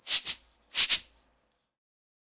African Shekere 1.wav